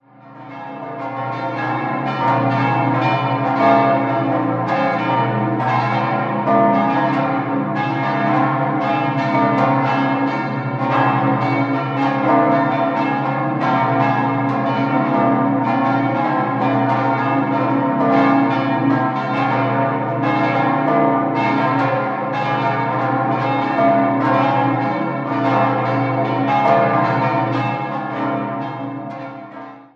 Die Glocken 1, 3 und 4 wurden 1658 (1), 1673 (4) und 1685 (3) von der Gießerei Schelchshorn in Regensburg gegossen, Glocke 2 stammt aus der Gießerei Spannagl (Regensburg) aus dem Jahr 1852 und die zwei kleinen Glocken wurden bei Hamm (Regensburg) im Jahr 1950 gegossen.
Regensburg_Emmeram.mp3